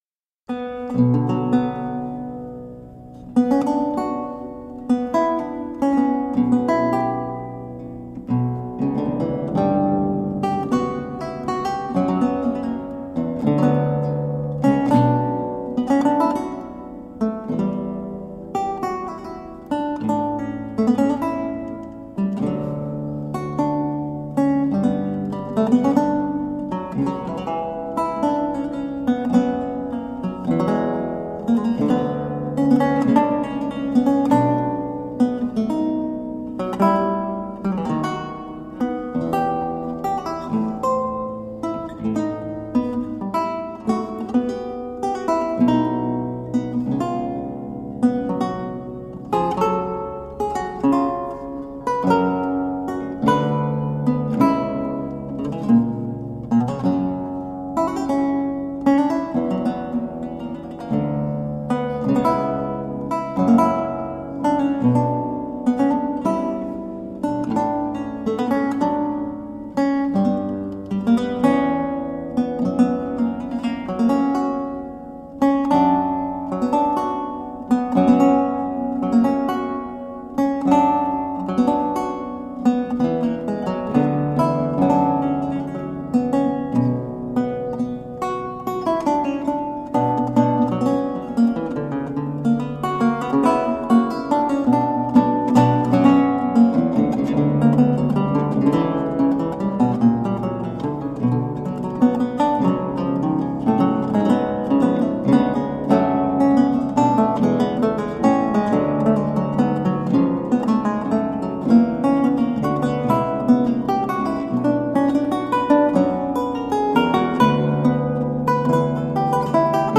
A marvelous classical spiral of lute sounds.